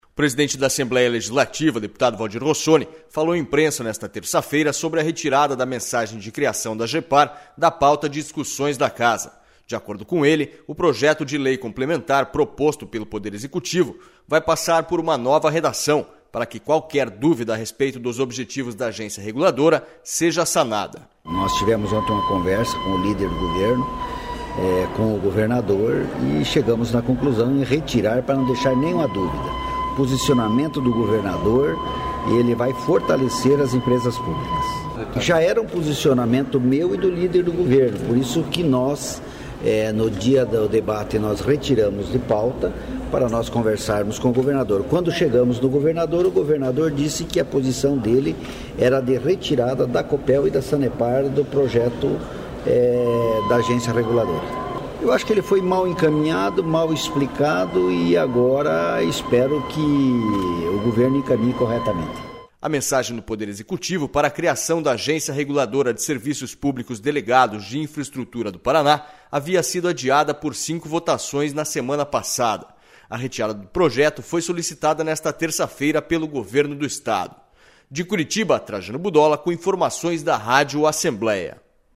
O presidente da Assembleia Legislativa, deputado Valdir Rossoni, falou a imprensa nesta terça-feira sobre a retirada da mensagem de criação da Agepar da pauta de discussões da Casa.//De acordo com ele, o projeto de lei complementar proposto pelo Poder Executivo vai passar por nova redação para que q...